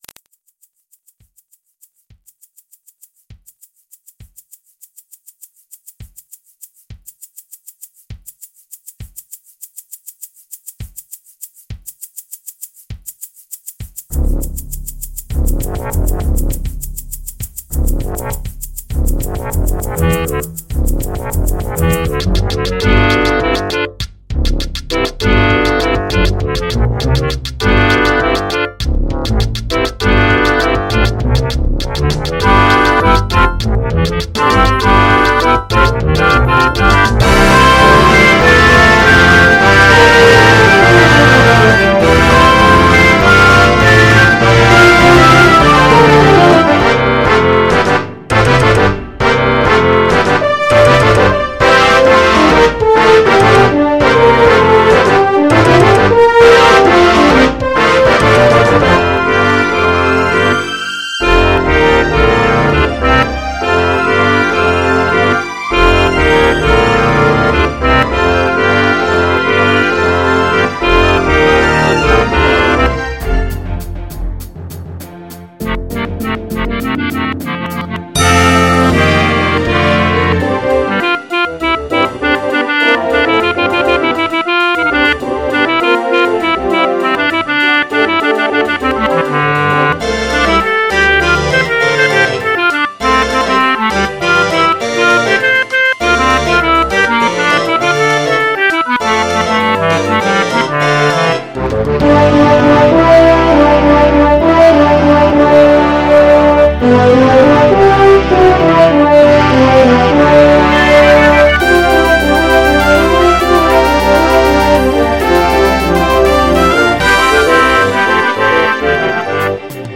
Light instrumental piece